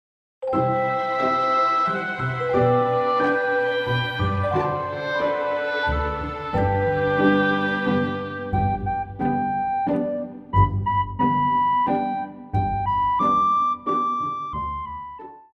高音直笛
管弦樂團
童謠,傳統歌曲／民謠,古典音樂
獨奏與伴奏
有主奏
有節拍器